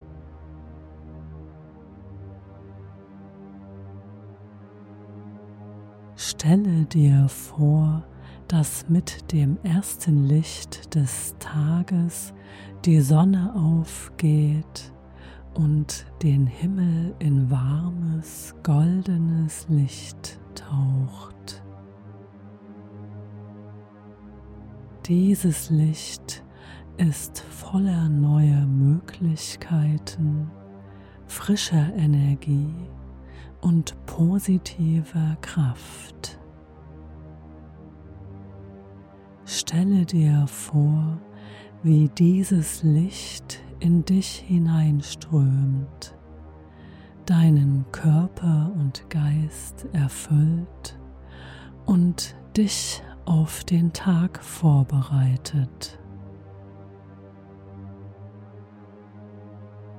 In dieser geführten Morgenmeditation begrüßt du den Tag und bereitest dich mental auf diesen neuen Tag vor.
Morgenmeditation Vorbereitung auf den Tag
horpr_meditation-vorbereitung-auf-den-tag.mp3